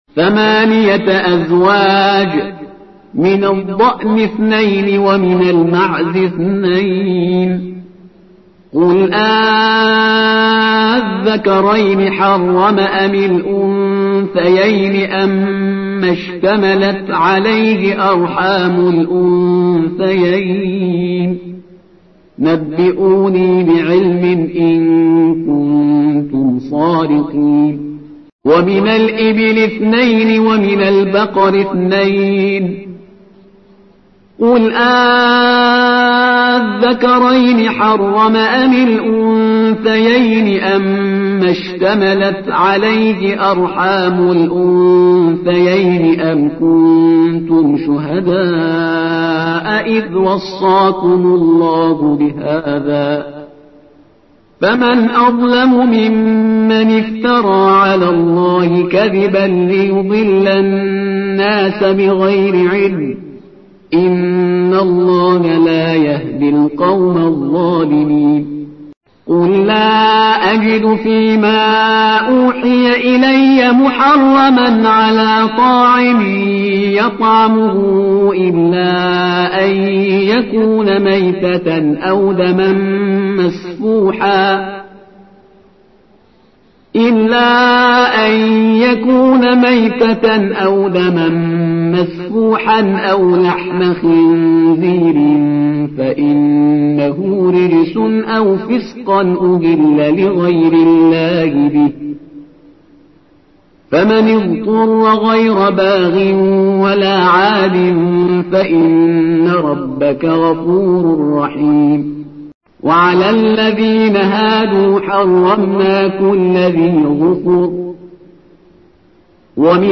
ترتیل صفحه ۱۴۷ سوره سوره انعام با قرائت استاد پرهیزگار(جزء هشتم)
ترتیل سوره(انعام)